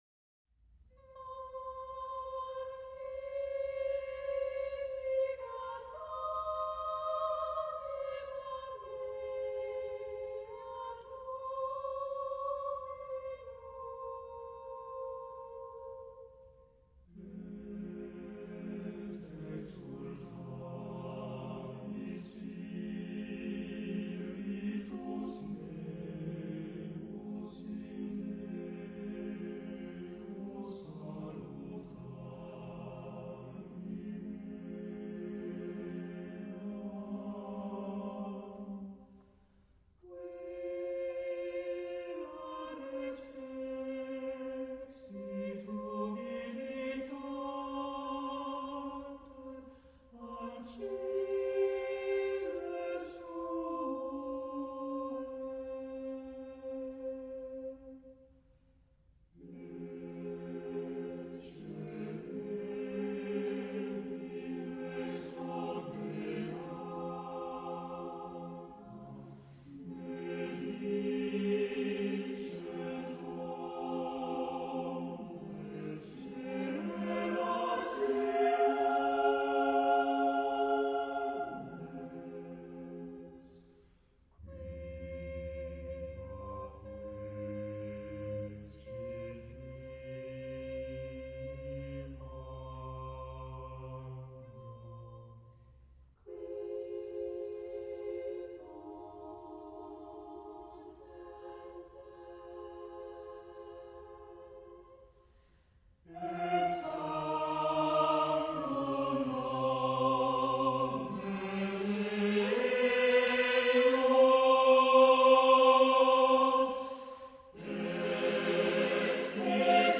SSAATTBB (8 voix mixtes) ; Partition complète.
Sacré. contemporain.
Consultable sous : 20ème Sacré Acappella